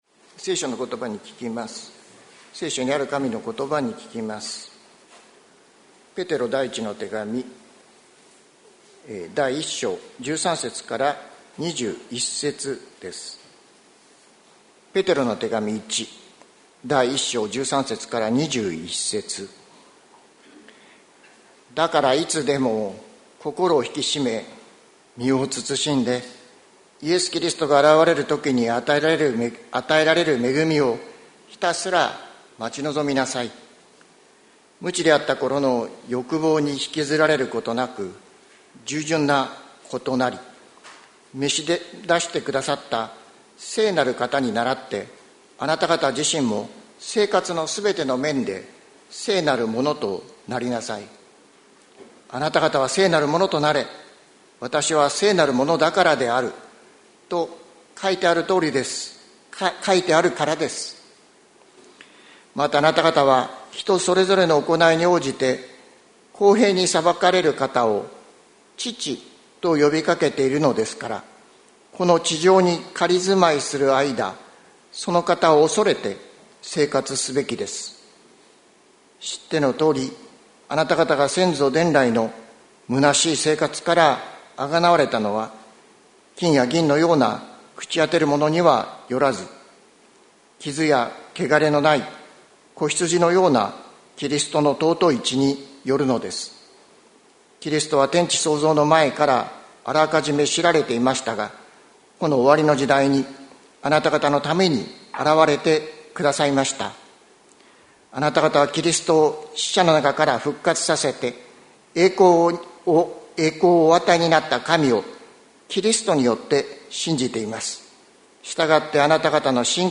2024年08月25日朝の礼拝「むなしさから救われよ」関キリスト教会
説教アーカイブ。